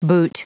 Prononciation du mot boot en anglais (fichier audio)
Prononciation du mot : boot